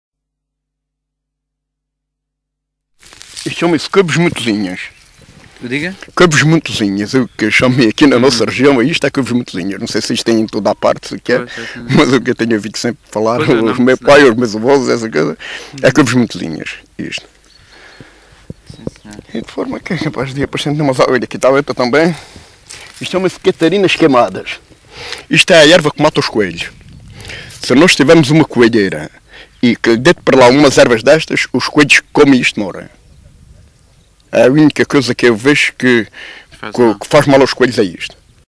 LocalidadeSapeira (Castelo de Vide, Portalegre)